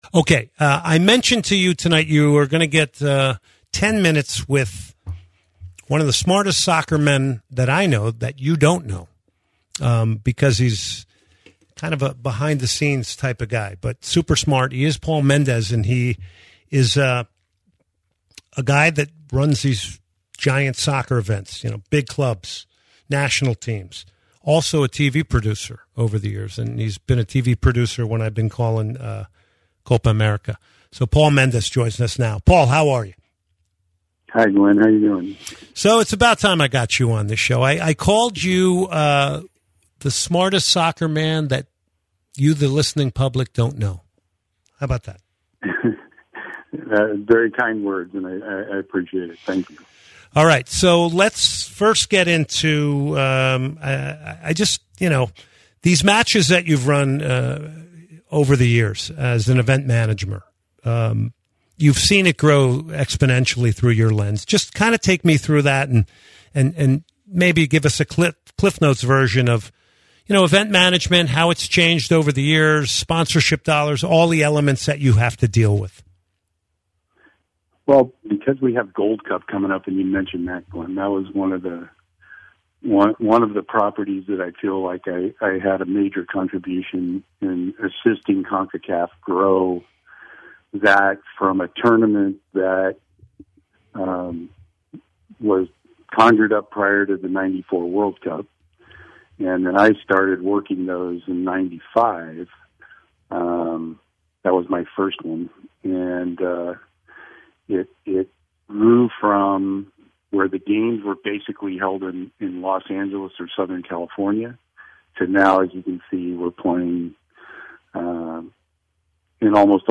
He joins the show for an interview about Houston soccer events ahead of the Gold Cup, & much more.